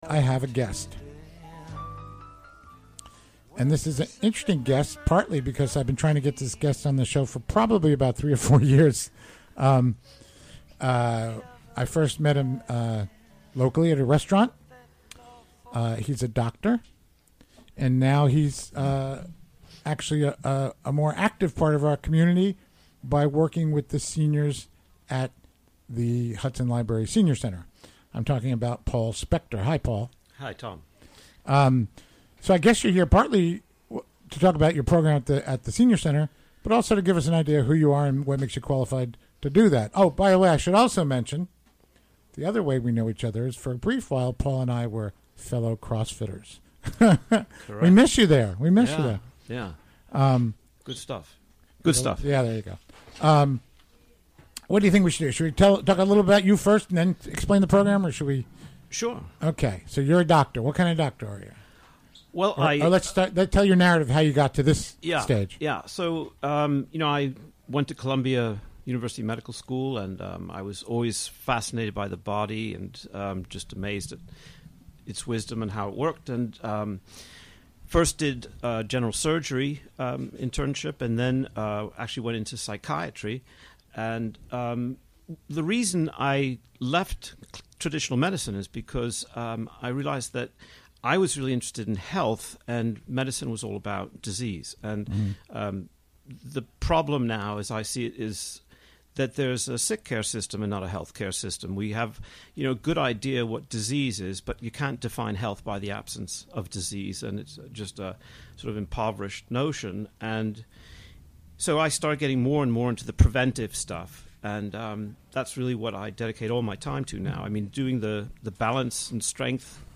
Recorded during the WGXC Afternoon Show of Thursday, February 8, 2018.